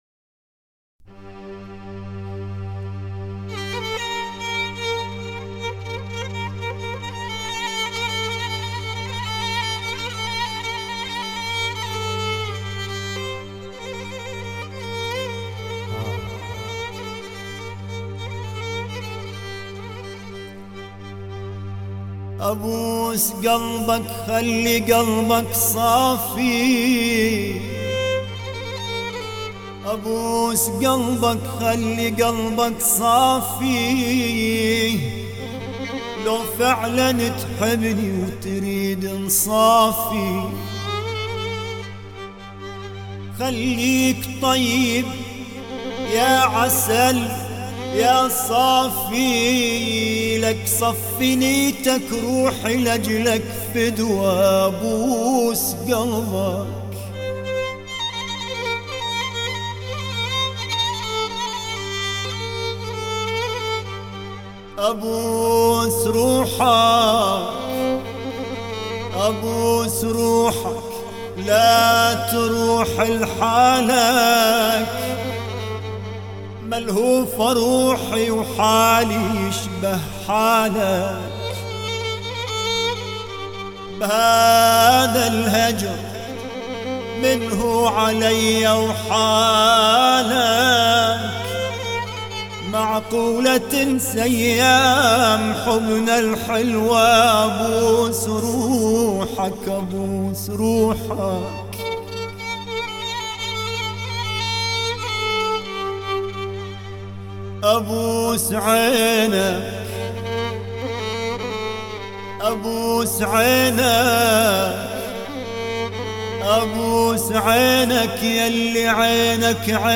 سبک موسیقی عربی و پاپ